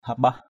/ha-ɓah/ (d.) thuốc nhuộm màu chàm.